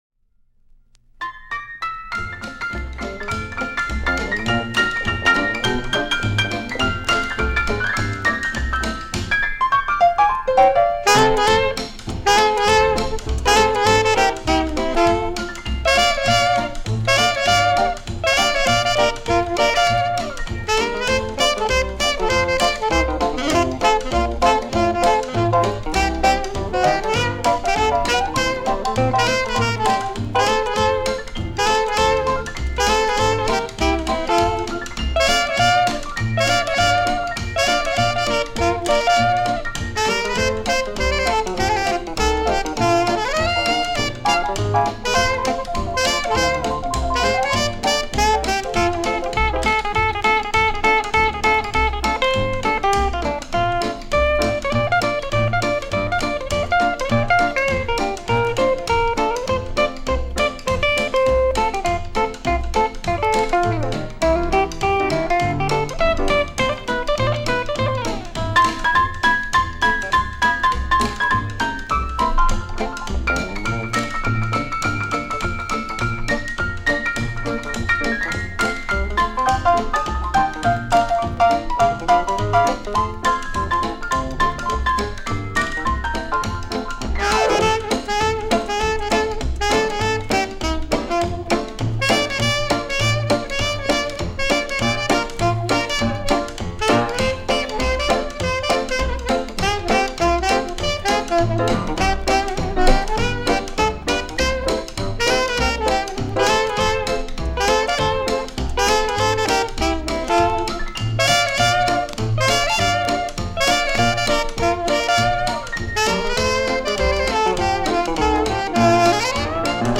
Быстрый фокстрот, оркестр